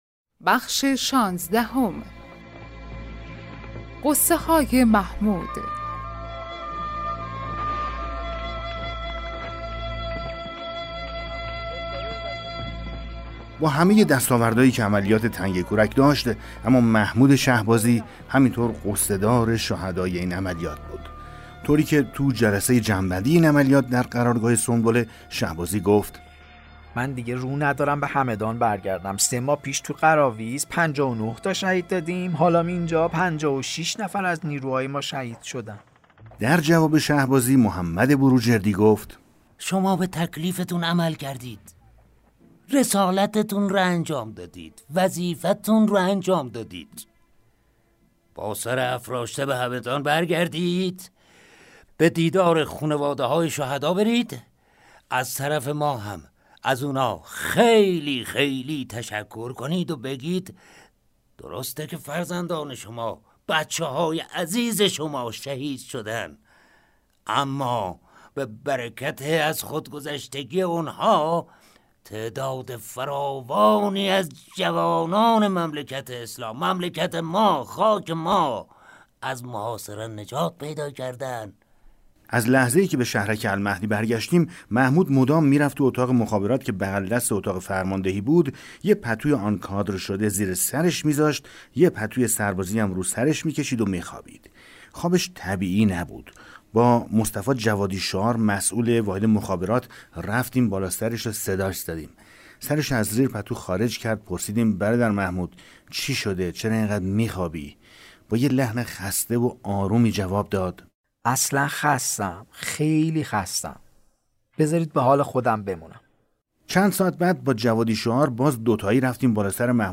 کتاب صوتی پیغام ماهی ها، سرگذشت جنگ‌های نامتقارن حاج حسین همدانی /قسمت 16